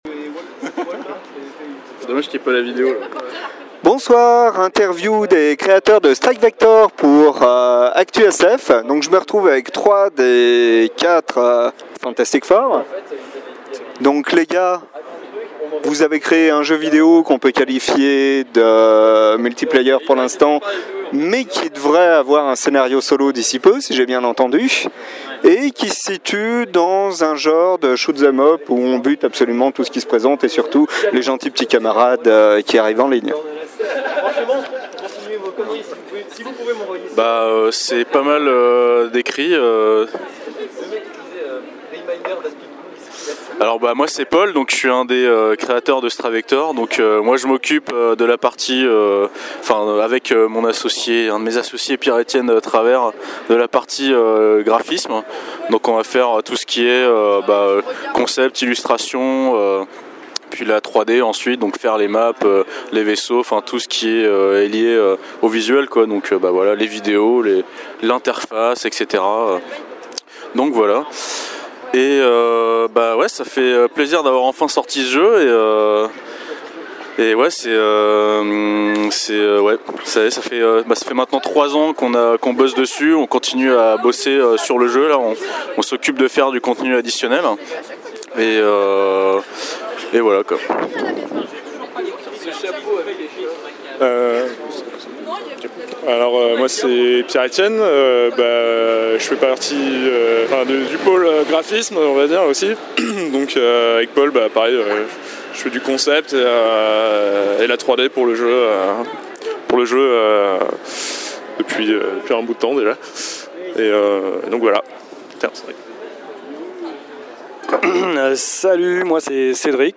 Interview sur Strike Vektor